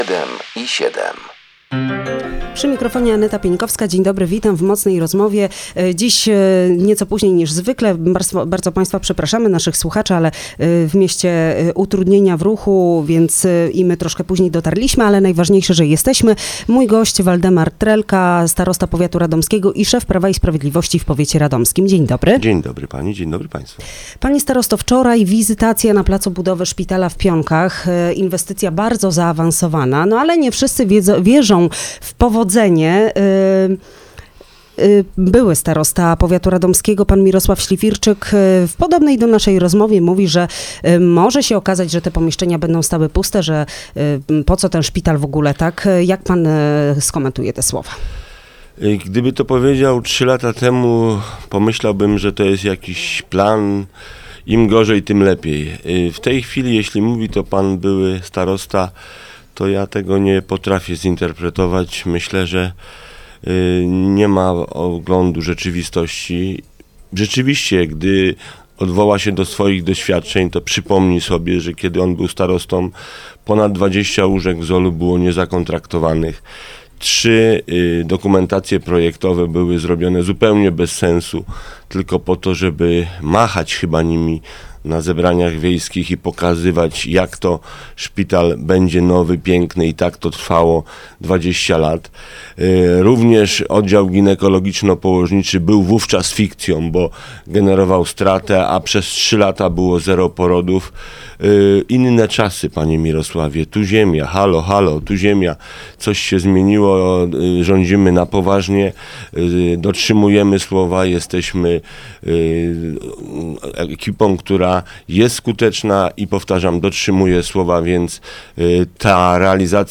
Waldemar Trelka, Starosta powiatu radomskiego i Szef PiS w regionie był gościem